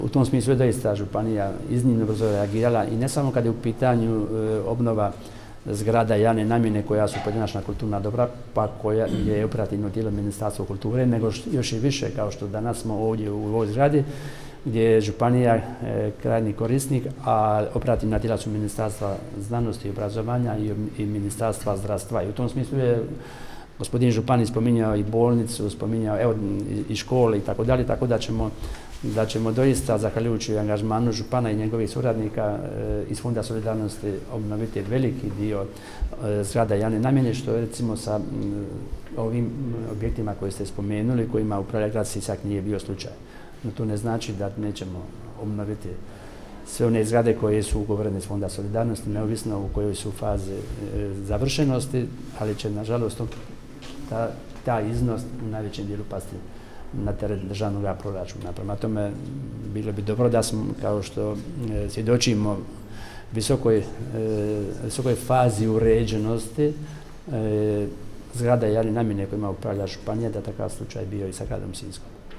“Bilo bi dobro da je Grad Sisak započeo na vrijeme s obnovom objekata koji su u njegovoj nadležnosti kao što je to primjerice učinila Sisačko-moslavačka županija”, rekao je danas u Sisku potpredsjednik Vlade i ministar prostornog uređenja, graditeljstva i državne imovine Branko Bačić